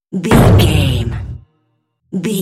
Cinematic drum stab hit trailer
Sound Effects
Atonal
heavy
intense
dark
aggressive